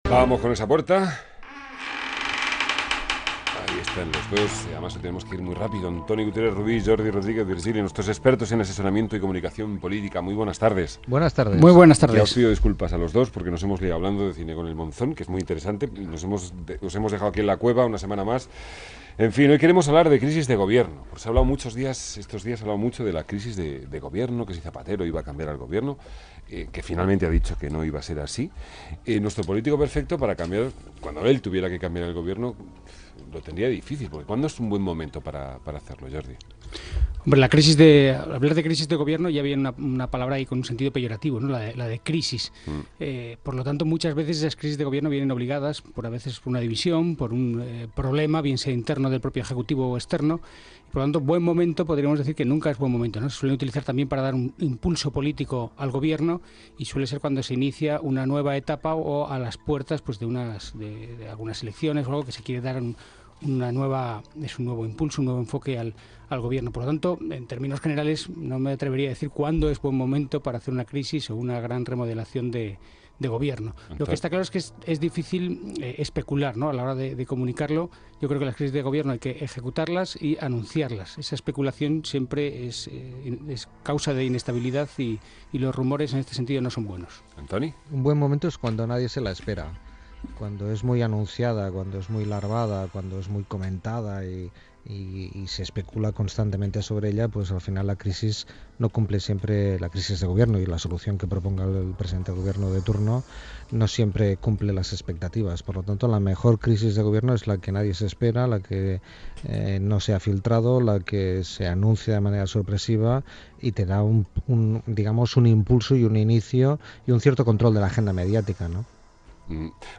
Emitido en: RNE. Asuntos propios (24.06.2010)